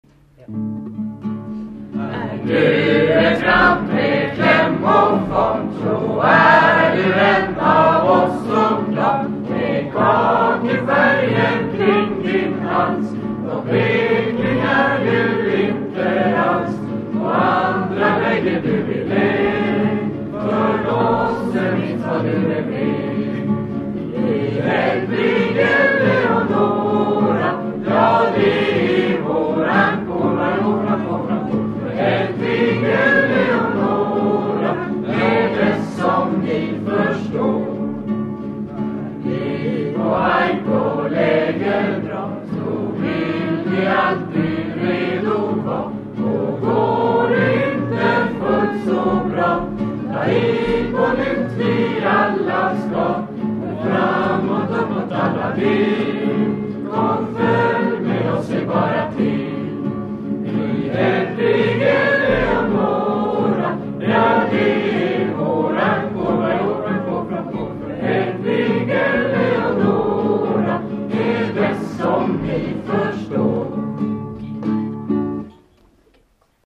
en klämmig sång att samlas under, den sjöngs även på Vargarnas årsmöten. Vem som gjort inspelningen vet jag inte, men den låter inte riktigt lika bra som när ja var liten,  som sagt "de va bättre förr"